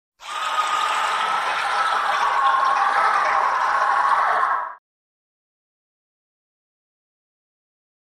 Alien Scream; Large Creature Screams Multi-tone.